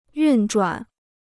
运转 (yùn zhuǎn): to work; to operate.